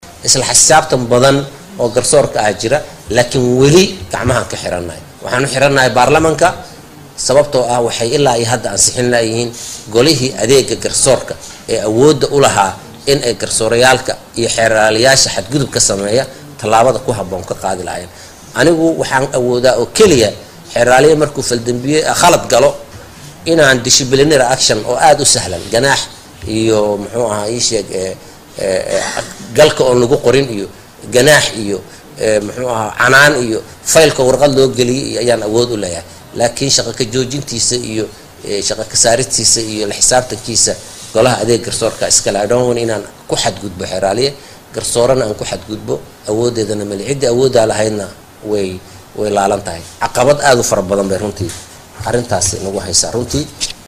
Xeerilaaliyaha Qaranka Axmed Cali Daahir oo la hadlay warbaahinta ayaa waxaa uu sheegay in garsoorka Soomaaliya ay u shaqeeyaan si cadaalad in ay caqabad ku yihiin baarlamaanka Soomaaliya.